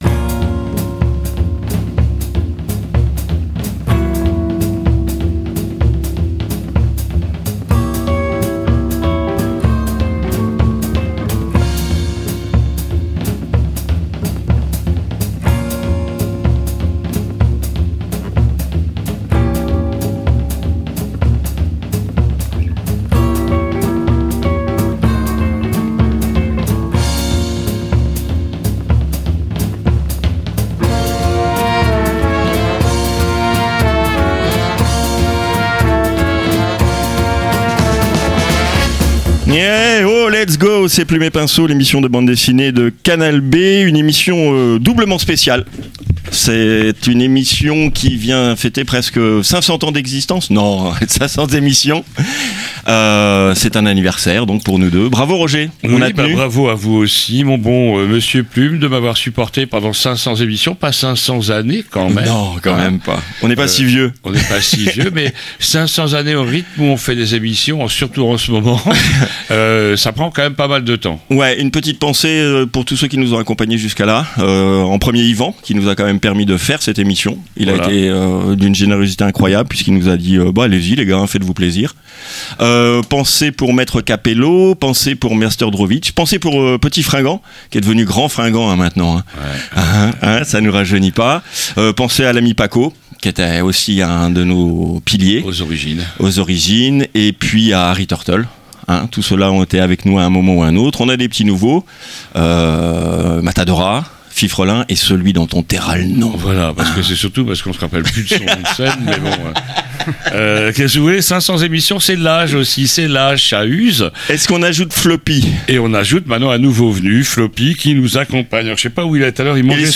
I - INTERVIEW